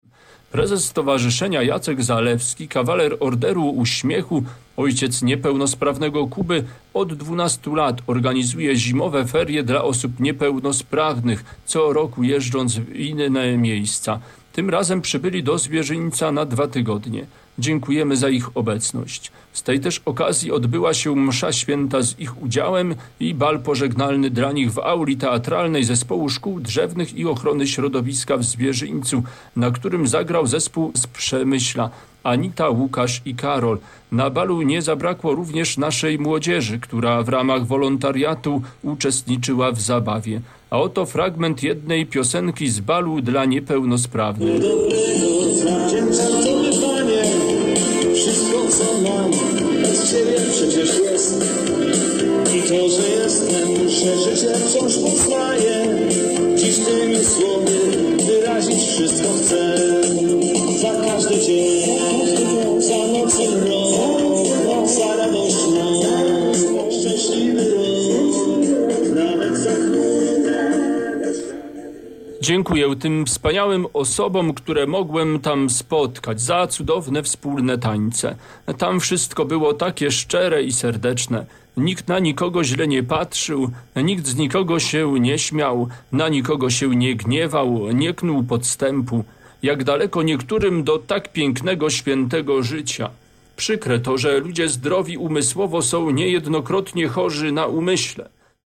Audycja w radiu Zamość dotycząca XII Zimowiska Dobrej Woli w Zwierzyńcu.